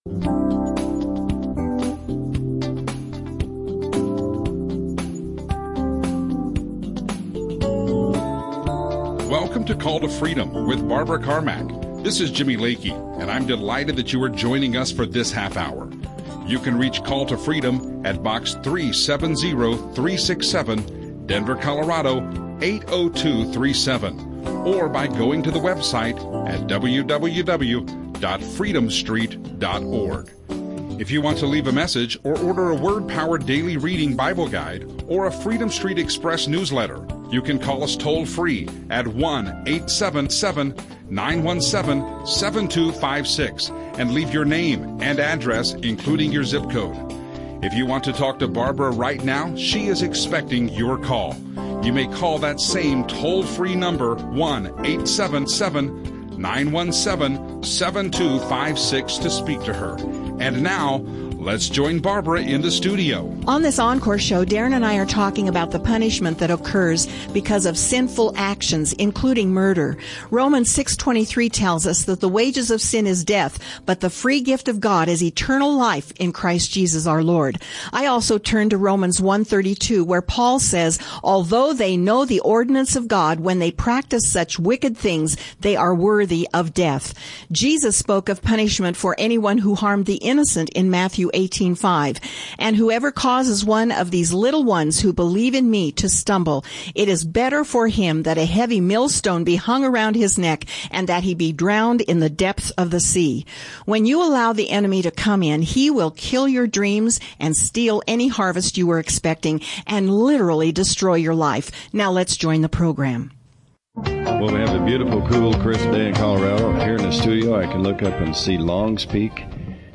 There are phone calls with very serious issues and the answers from the Word are comforting and healing to the hearer.